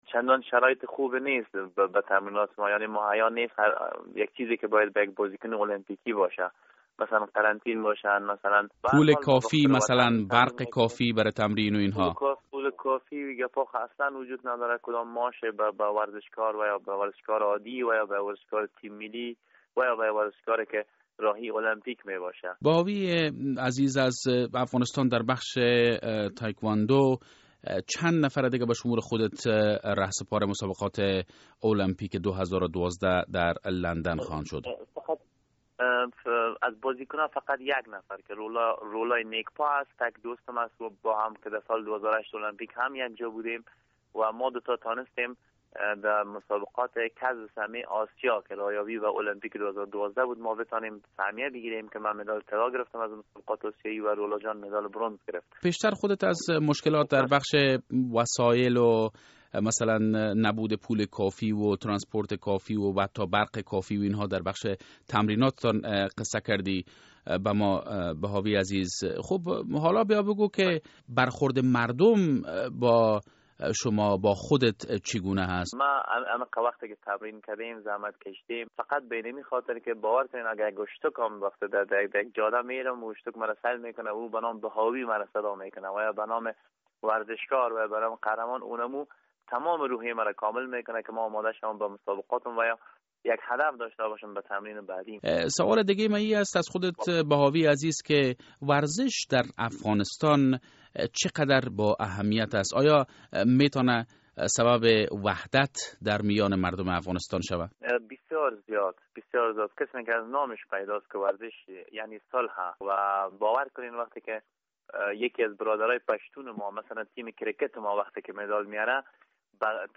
مصاحبه با نثار احمد بهاوی در مورد مسابقات تکواندو در لندن